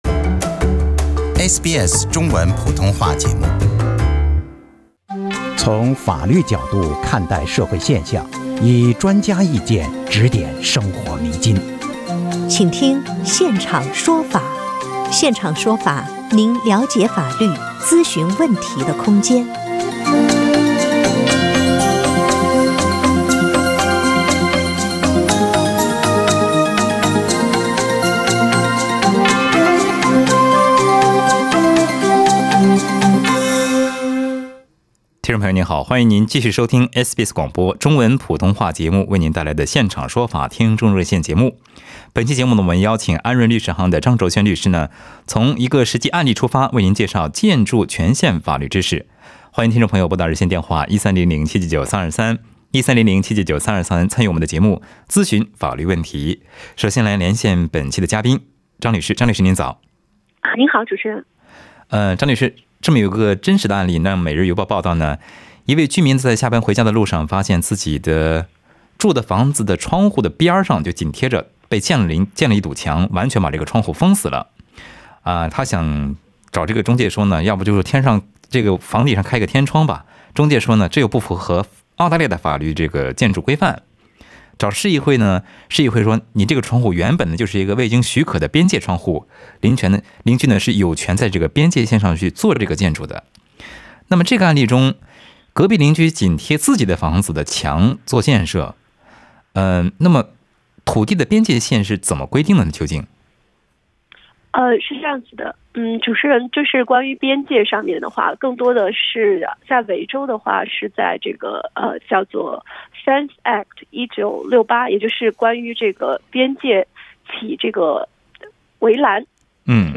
本期《现场说法》听众热线节目
还有听众在节目中咨询了讨薪，以及邻居树影响到自己房产建设等问题。